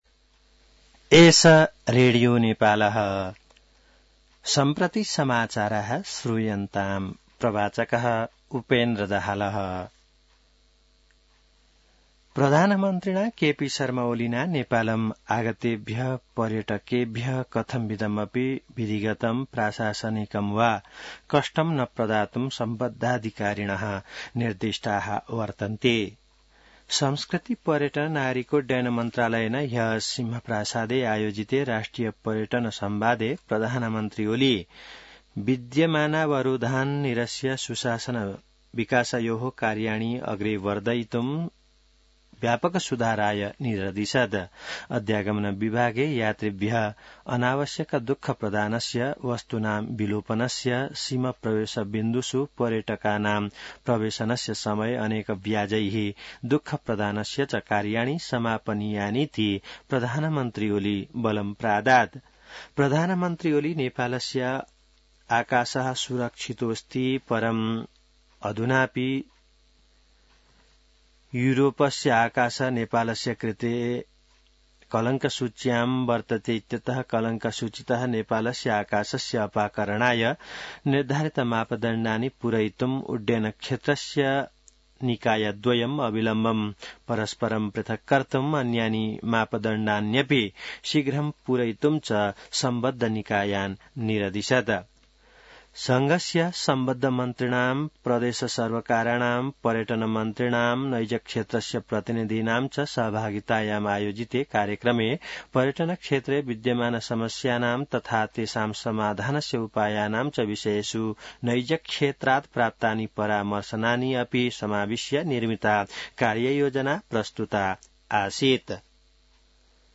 संस्कृत समाचार : १३ जेठ , २०८२